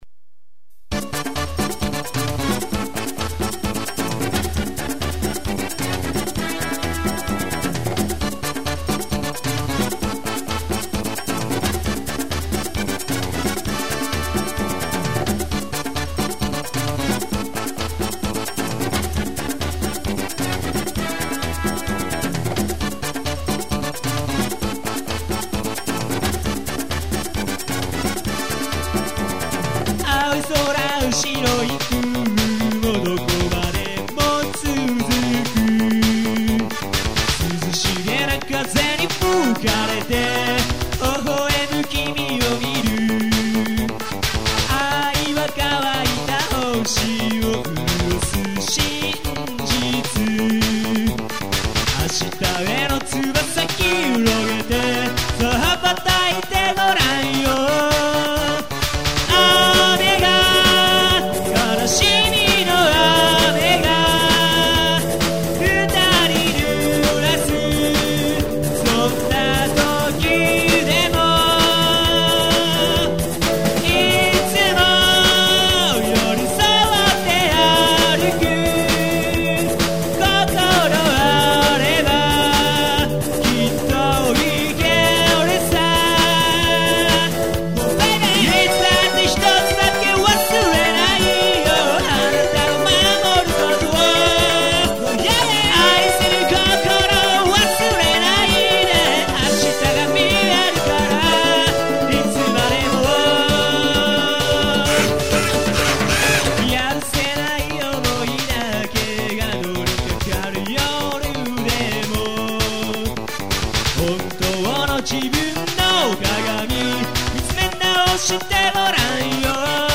ラストの超絶シャウトがまたミスマッチでかっこよい。ギターソロも頑張った。